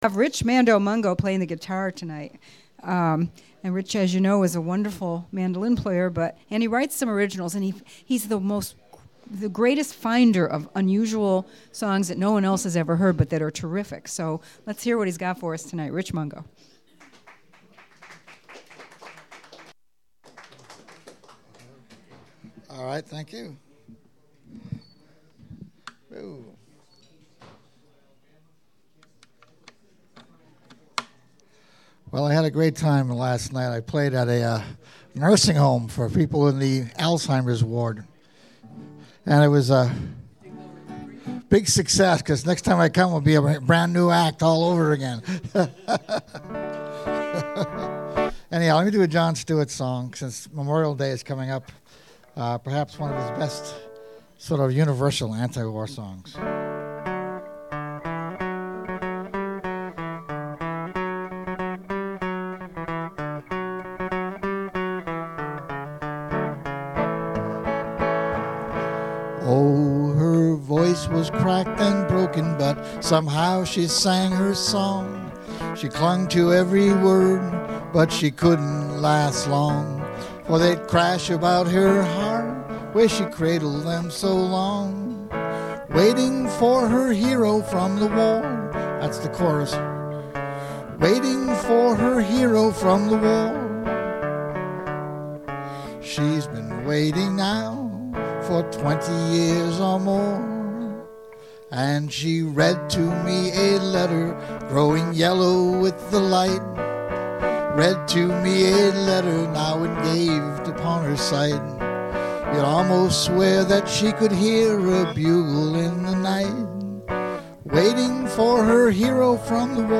[Knowledge Domain Interpretation]   Nourish Restaurant Open Mike Private Files
Files with a title starting raw or Untitled have only been track-level volume adjusted and are not joined, clipped, equalized nor edited.
raw Nourish Restaurant Open Mike, 5/10/11